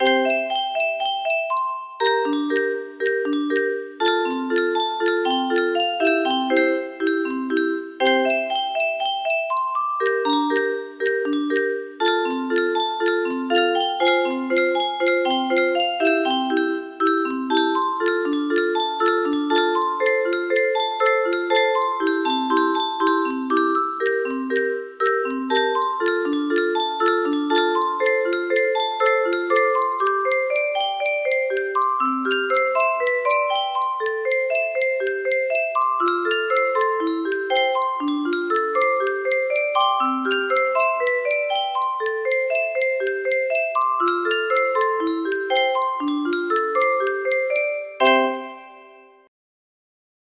Natural music box melody
Grand Illusions 30 (F scale)